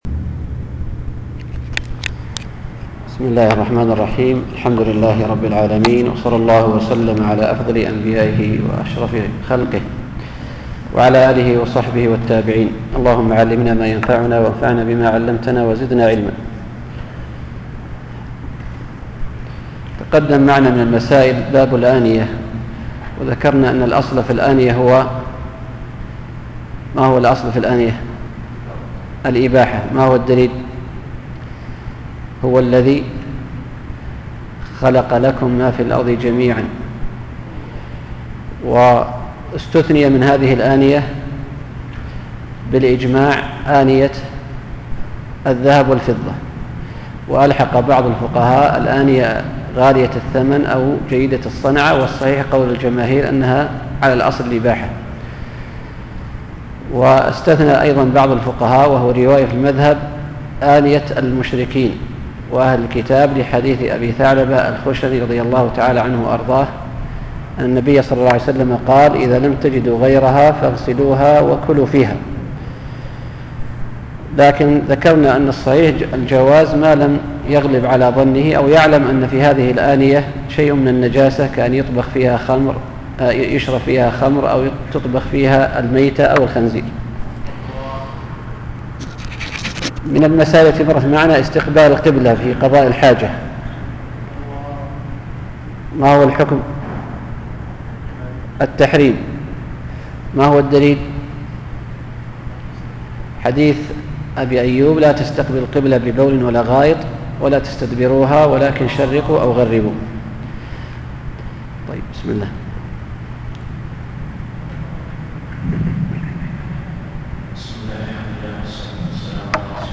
الدرس الرابع: باب الوضوء – باب المسح على الخفين